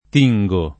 t&jgo], -gi — pass. rem. tinsi [t&nSi]; part. pass. tinto [